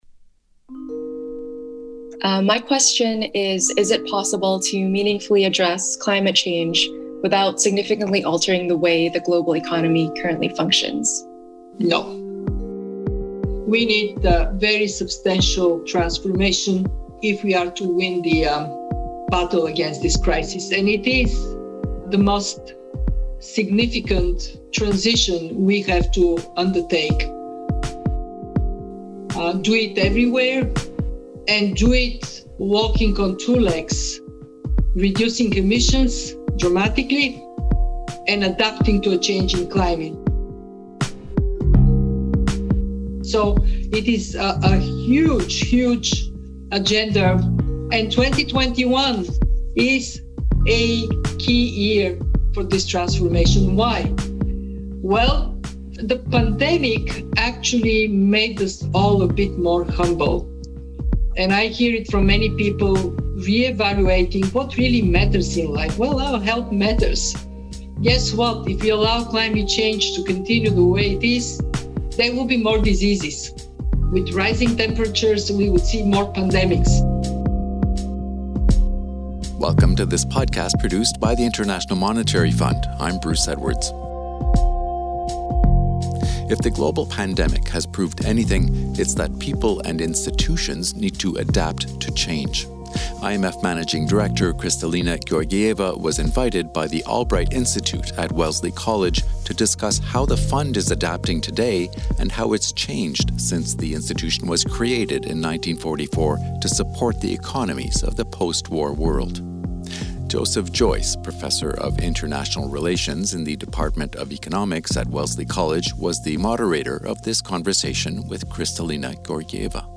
IMF Managing Director Kristalina Georgieva was invited by the Albright Institute of Global Affairs at Wellesley College to discuss how the Fund is adapting to the current needs of the global economy and the extent to which the institution has had to rethink its strategies since it was created in 1944 to support economies of the post-war world.